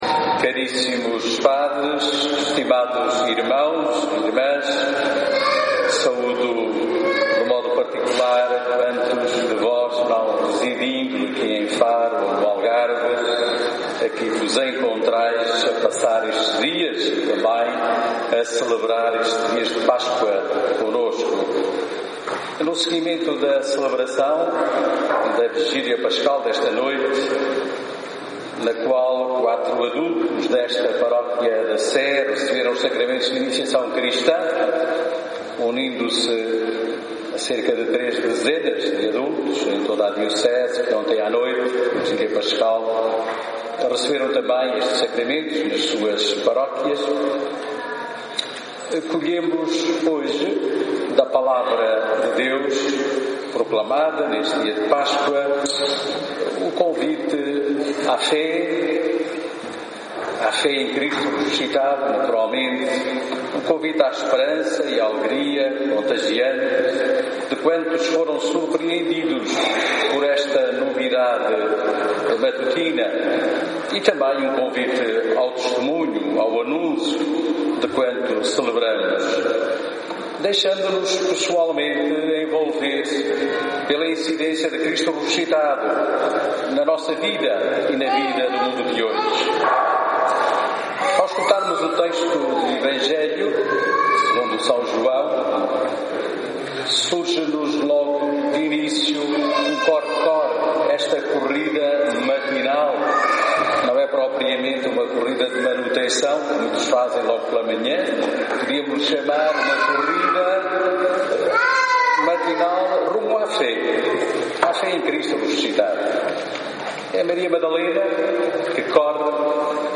Homilia_domingo_pascoa_2017.mp3